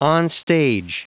Prononciation du mot onstage en anglais (fichier audio)
Prononciation du mot : onstage